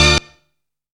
ORCHY STAB.wav